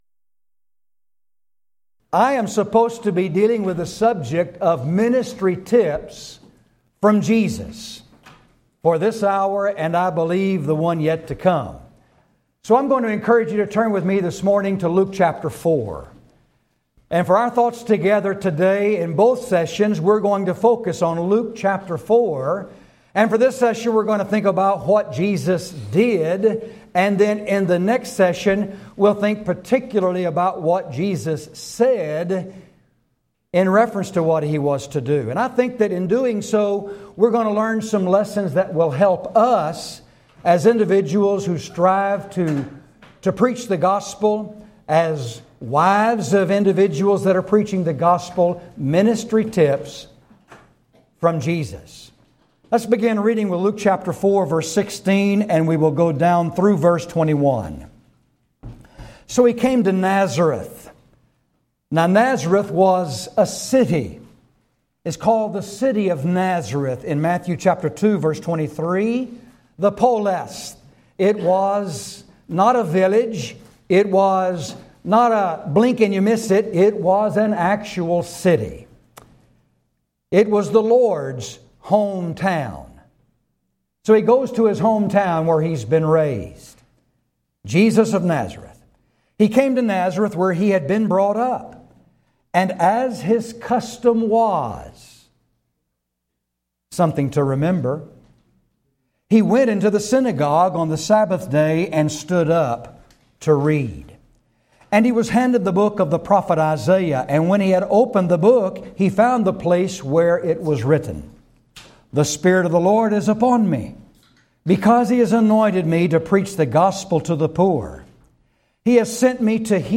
Preacher's Workshop
lecture